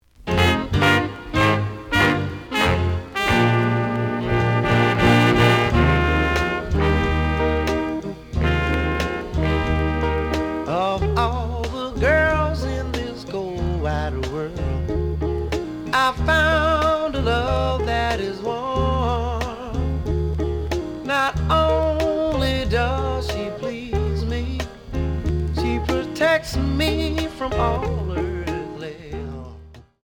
The audio sample is recorded from the actual item.
●Genre: Soul, 60's Soul
Slight edge warp. But doesn't affect playing.